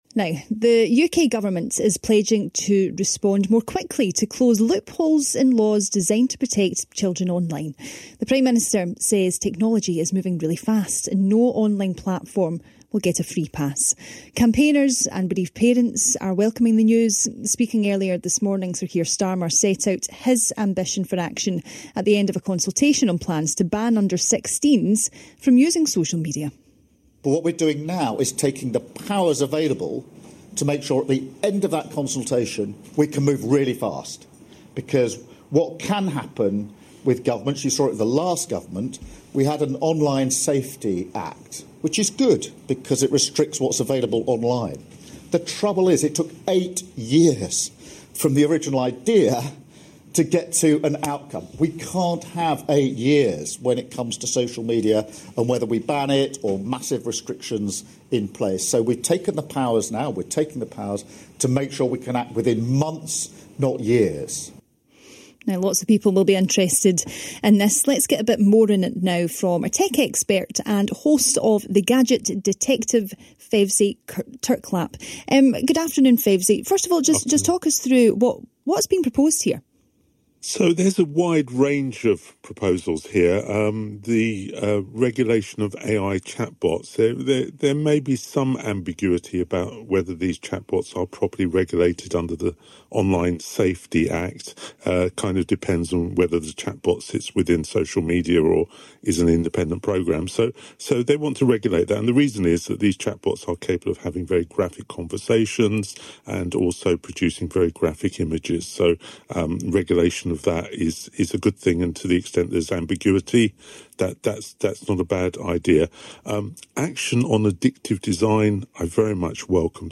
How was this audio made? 16th February 2026 - Discussing Online Safety on BBC Radio Scotland